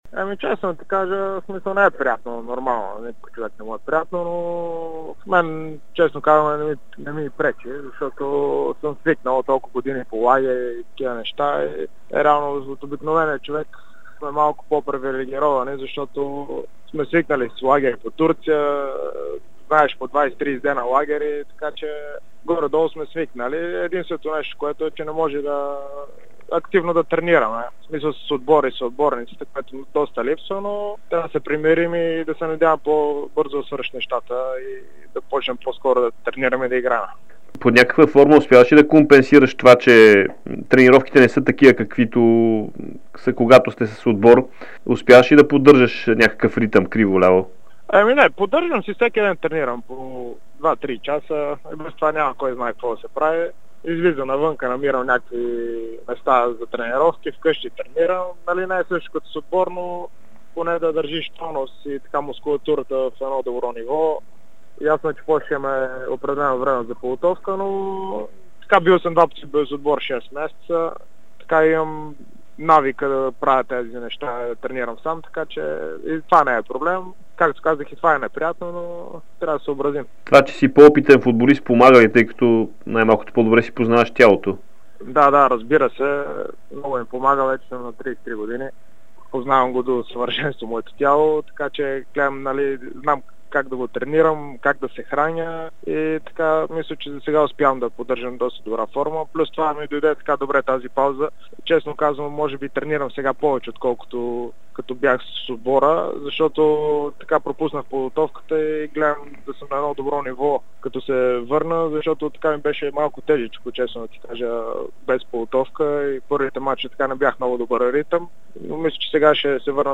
Футболистът на Ботев Пловдив Иван Бандаловски даде специално интервю за Дарик радио и dsport. Крайният бранител разказа за своя престой в сръбския гранд Партизан и как развиват футбола си западните ни съседи.